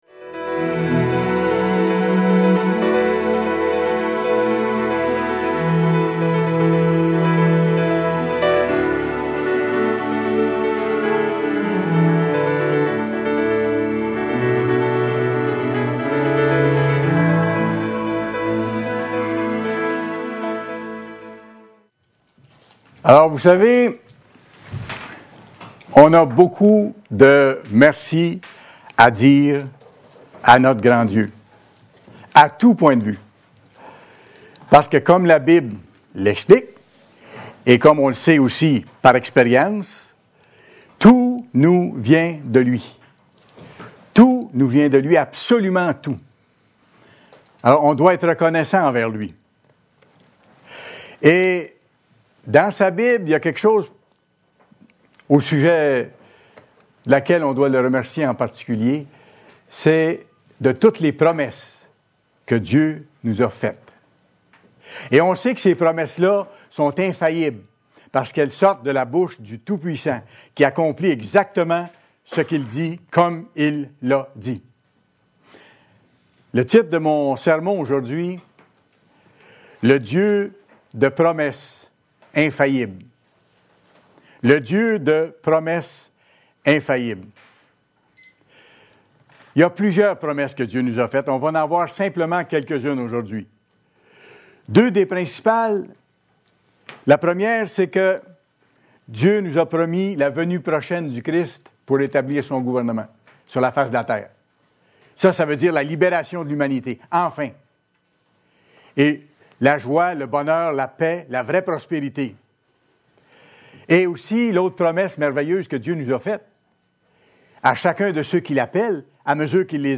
Aussi, nous devons Lui être reconnaissants pour tout ce qu’Il accomplit et pour Ses promesses. Dans ce sermon, nous allons étudier quelques promesses faites par Dieu.